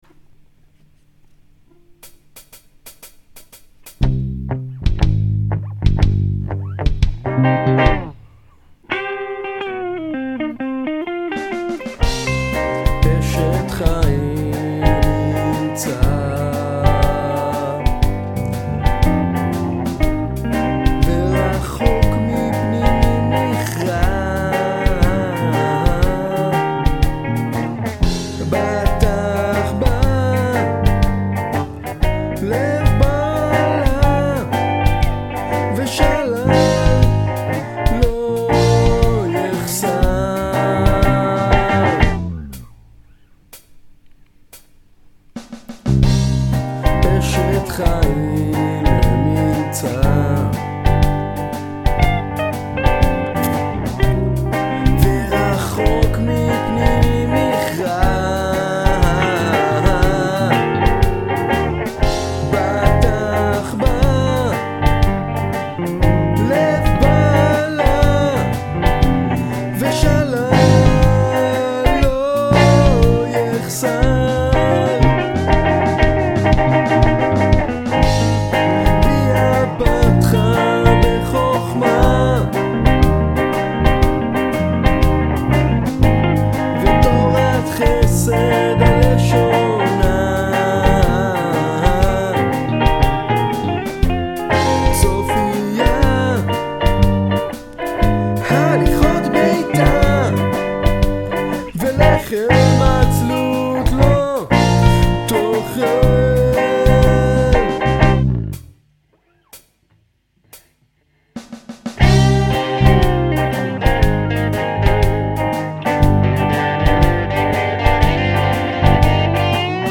אשת חיל - בלוז.